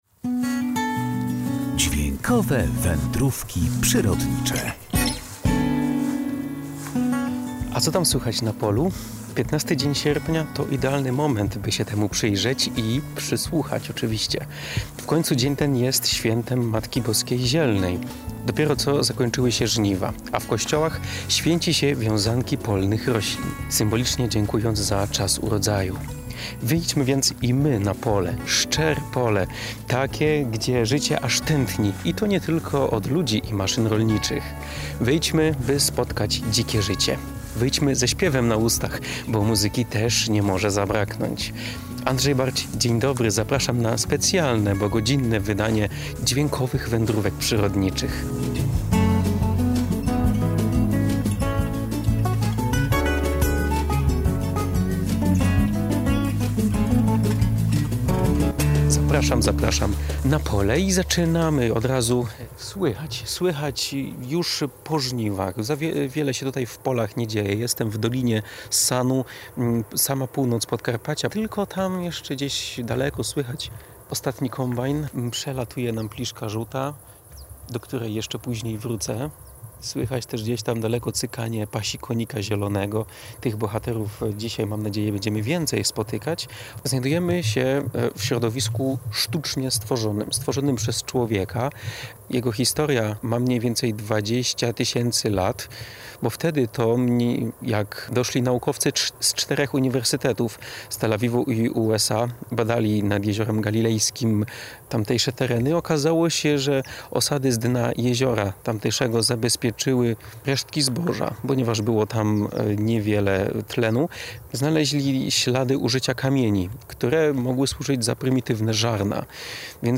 Co tam słychać w polu? Piętnasty dzień sierpnia był idealnym momentem, by się temu przyjrzeć i przysłuchać.